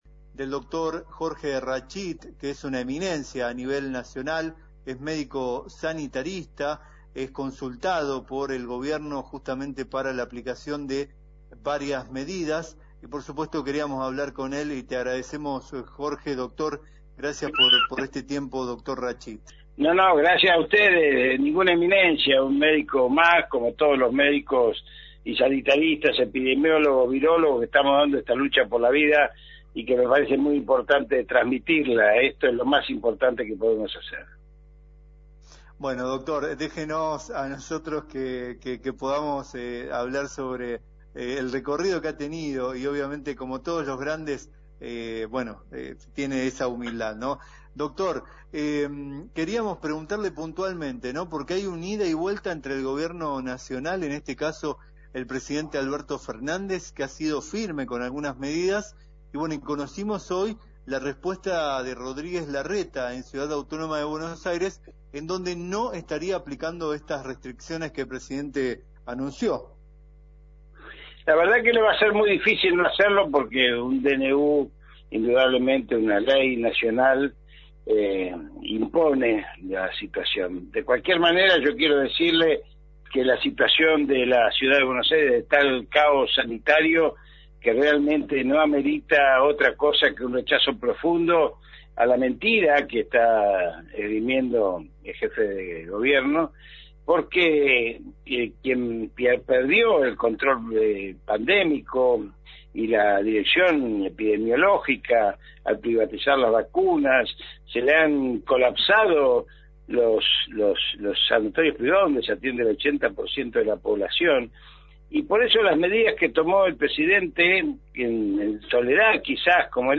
en diálogo con